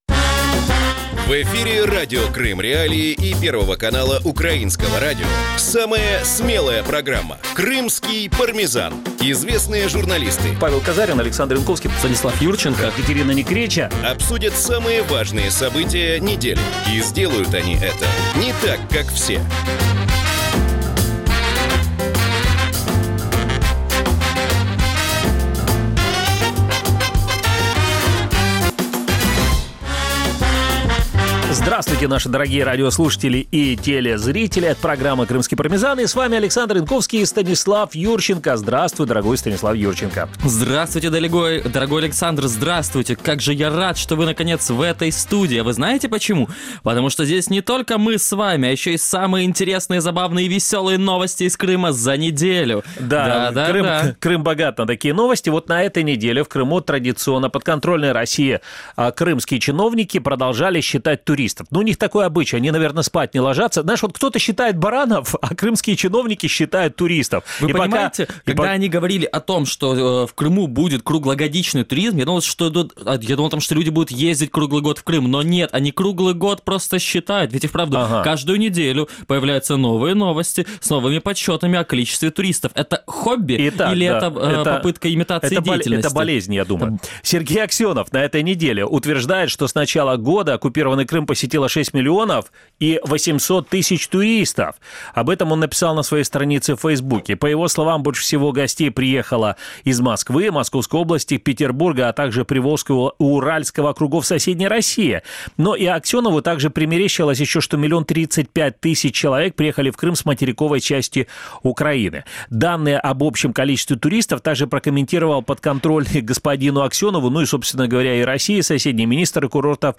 Время эфира в Крыму 18:00 (17:00 по киевскому времени) в эфире Радио Крым.Реалии (105.9 FM), а также на сайте Крым.Реалии. Также программу можно слушать и на волнах украинского Радио НВ.